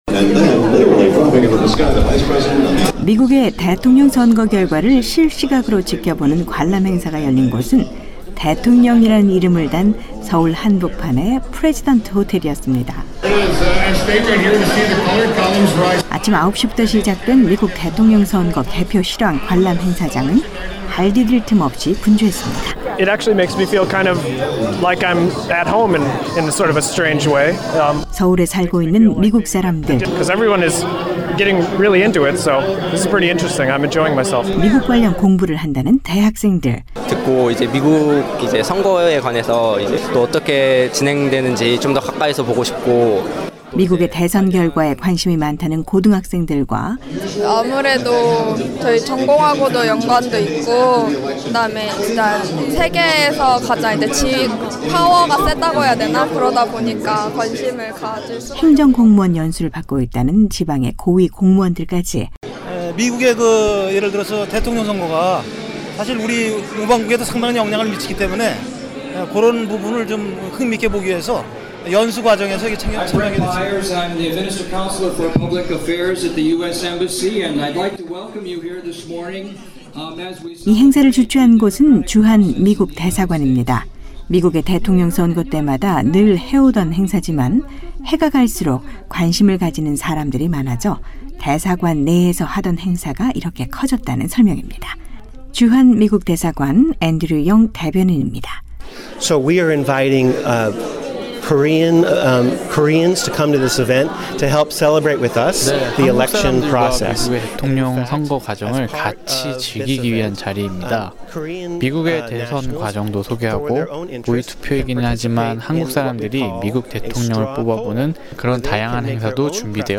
오늘 서울에서는 선거 개표방송을 서울시민들과 함께 지켜보는 행사가 열렸다는데요. 500여 명의 학생과 전문가들이 참석했고, 투표결과를 예측해보는 모의 투표도 진행됐다고 합니다. ‘안녕하세요. 서울입니다.’ 미국 대통령선거 개표실황 관전행사장 분위기를 전해드립니다.